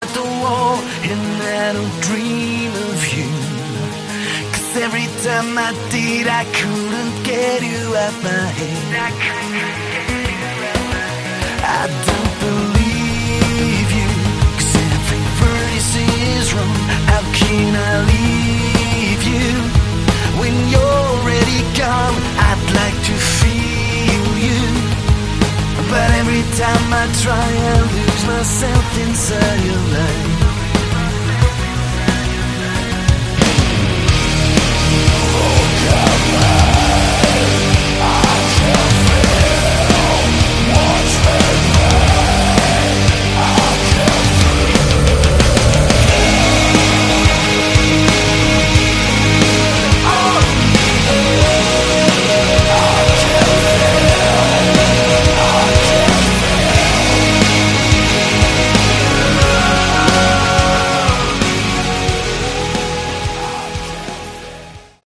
Category: Modern Synth Hard Rock